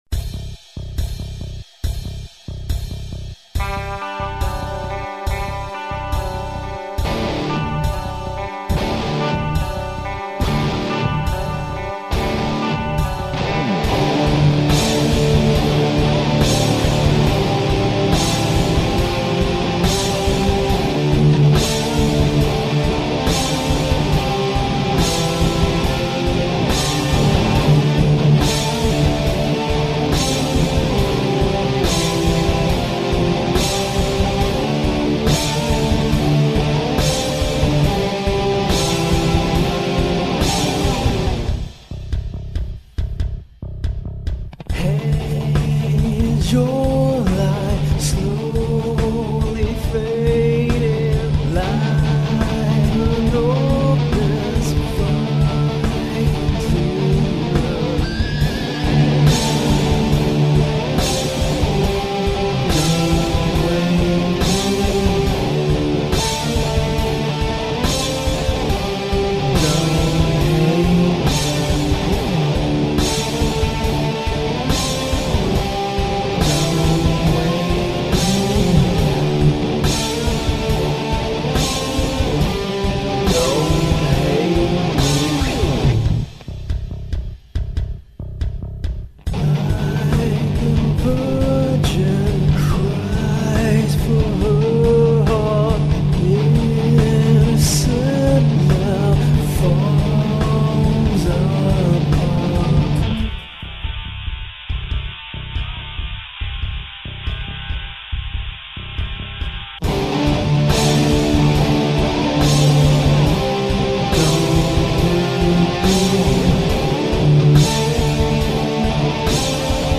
solo acoustic act